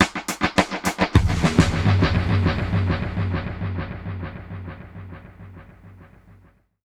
Index of /musicradar/dub-drums-samples/105bpm
Db_DrumsB_EchoKit_105-04.wav